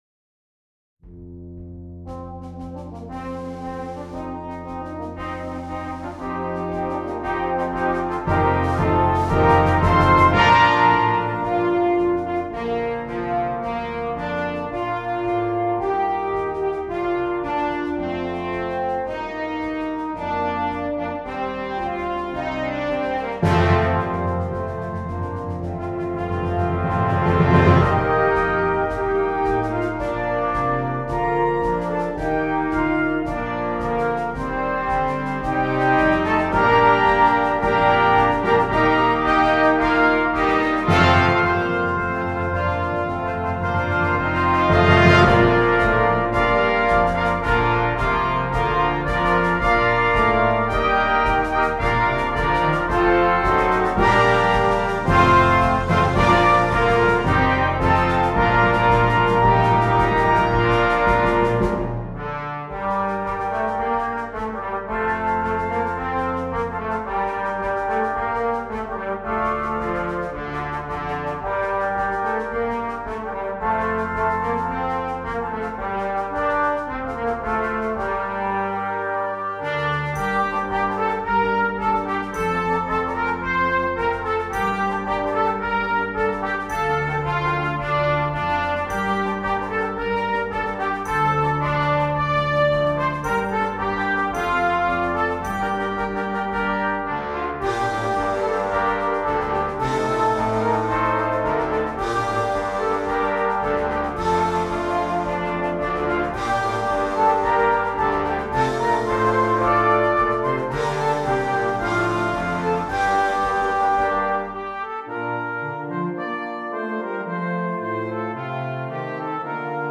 Brass Band